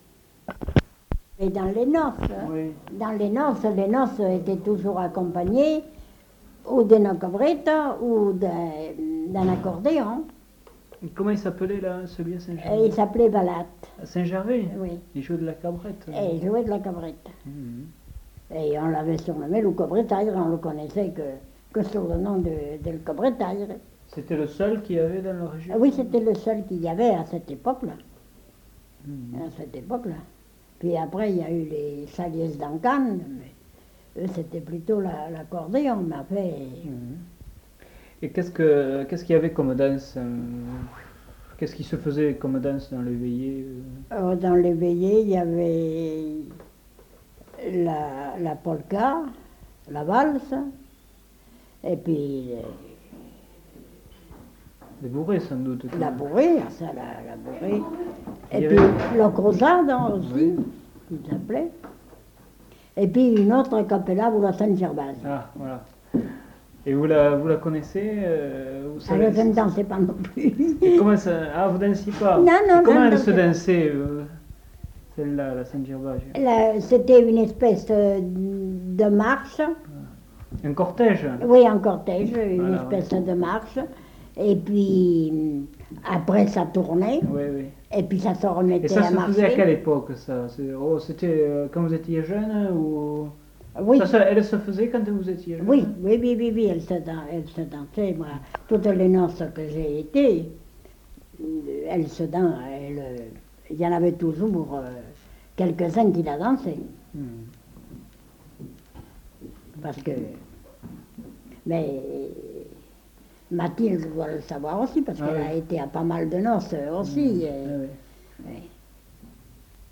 Lieu : Tournay
Genre : témoignage thématique
Instrument de musique : cabrette